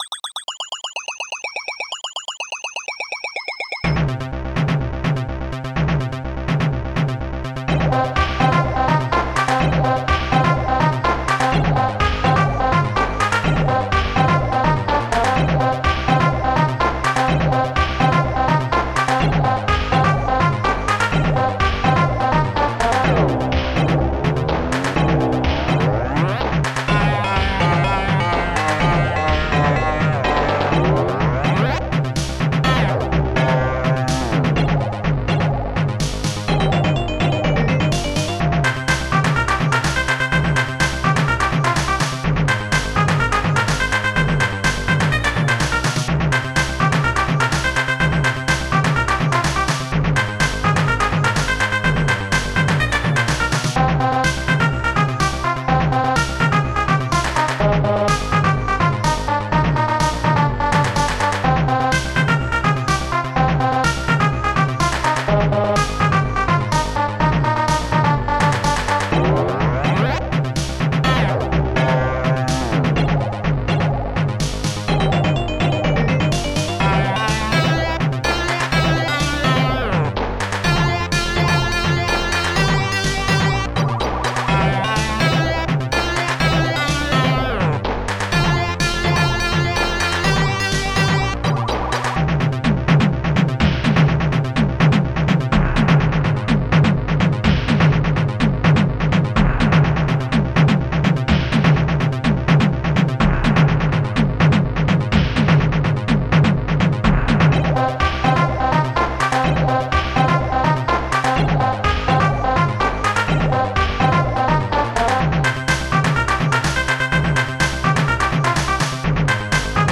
SoundTracker Module
Instruments korgbass electom popsnare2 claps1 metalkeys tinewave celeste blubzing